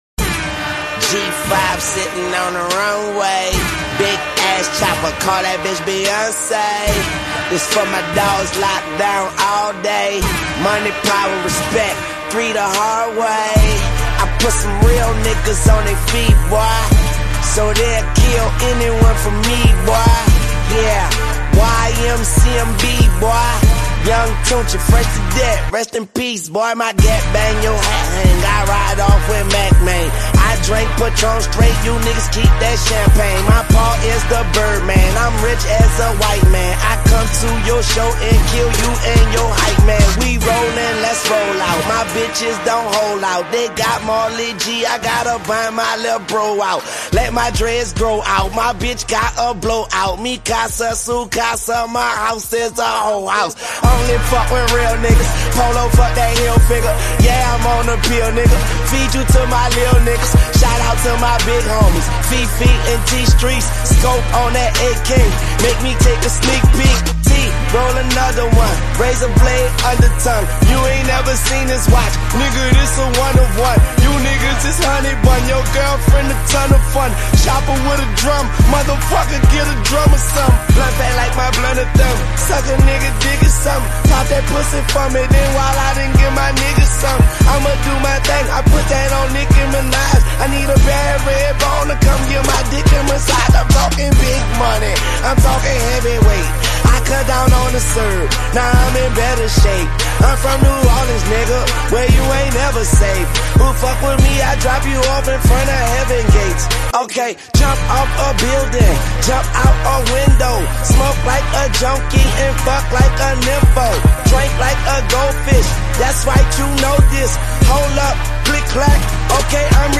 Genre Hip Hop